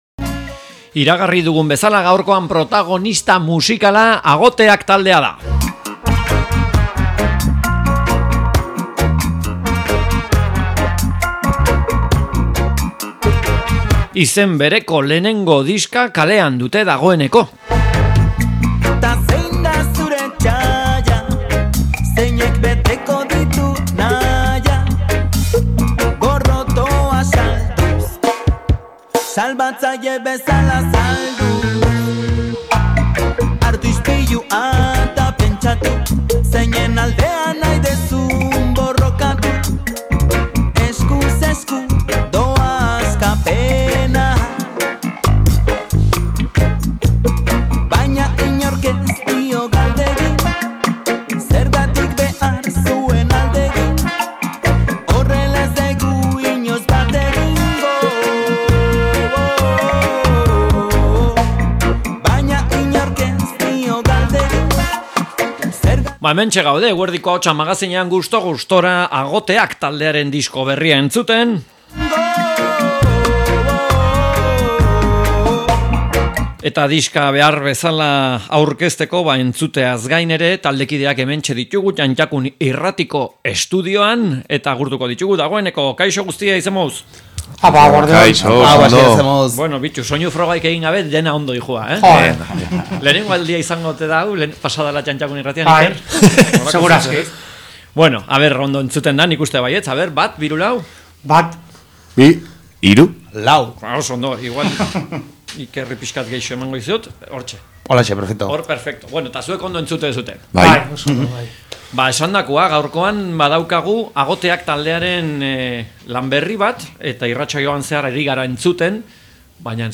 Agoteak taldeari elkarrizketa